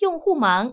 ivr-user_busy.wav